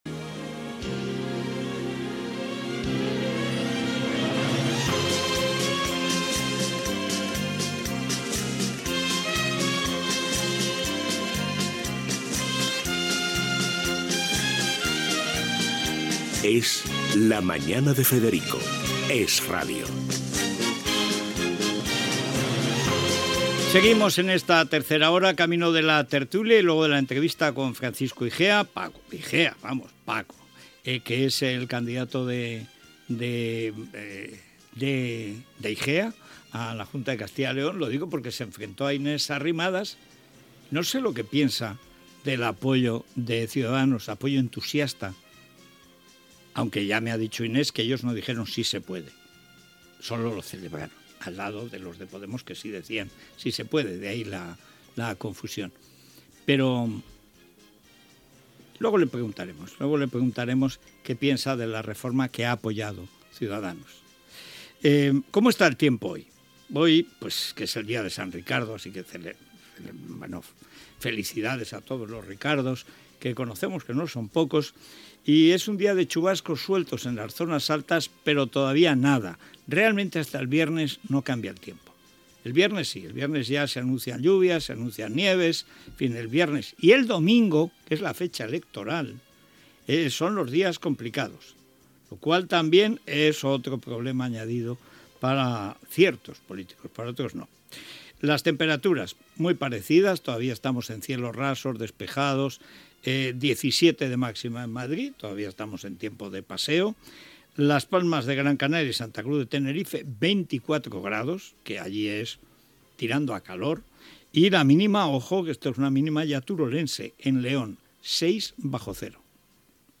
Careta del programa
Info-entreteniment